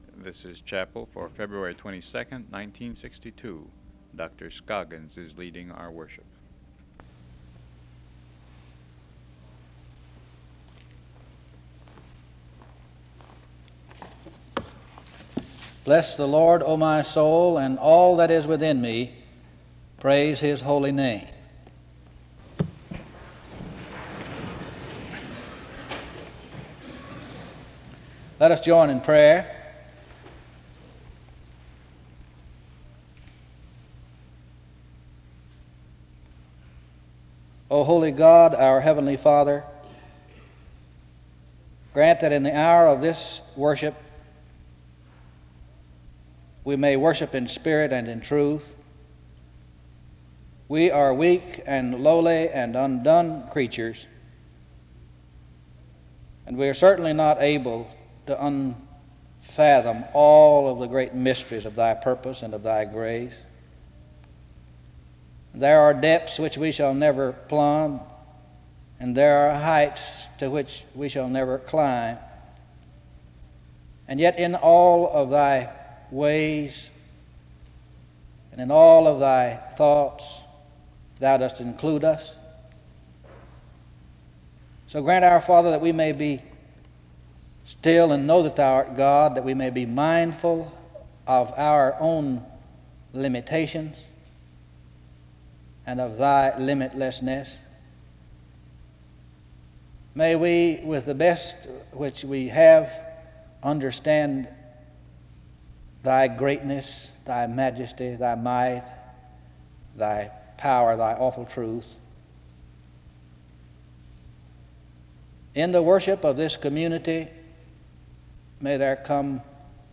A scripture reading and prayer begins the service from 0:16-4:37.
SEBTS Chapel and Special Event Recordings SEBTS Chapel and Special Event Recordings